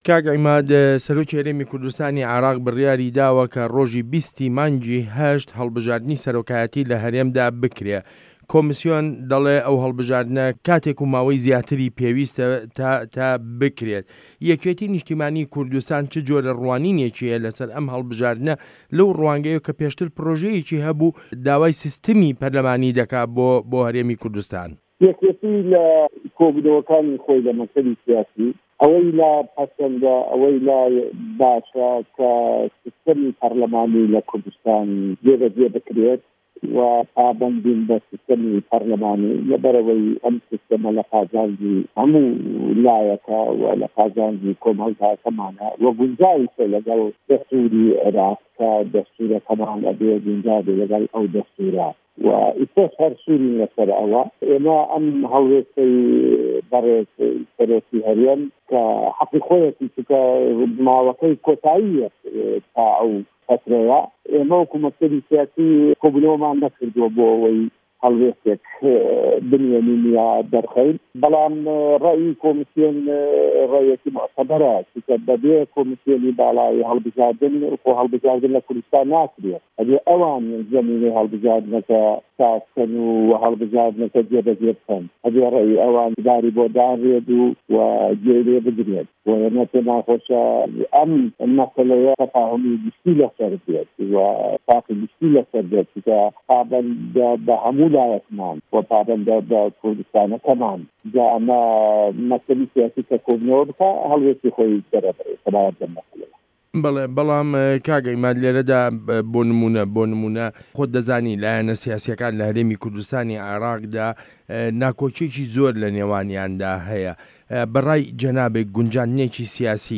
وتووێژ له‌گه‌ڵ عیماد ئه‌حمه‌د